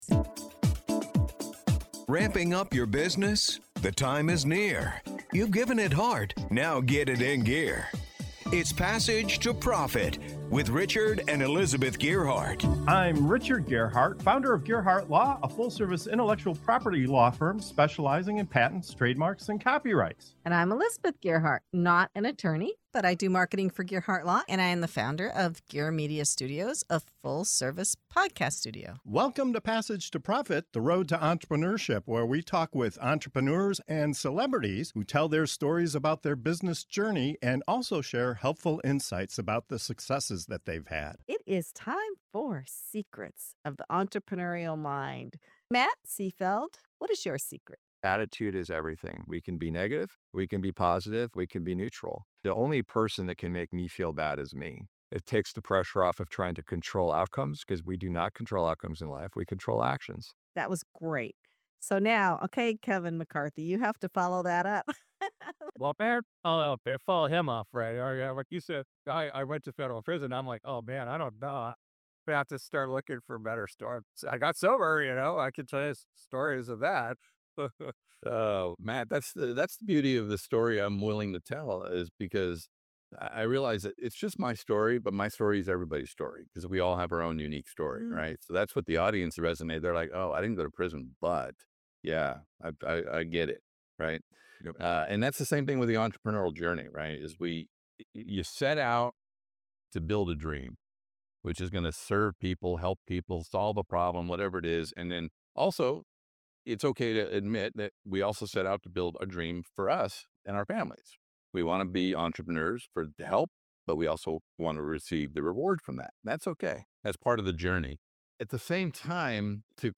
In this inspiring episode of "Secrets of the Entrepreneurial Mind", our guests open up about the real struggles, mindset shifts, and personal growth that fuel entrepreneurial success. From prison to purpose, failure to evolution, and blind spots to bold moves, they share how attitude, curiosity, and self-awareness shape the journey.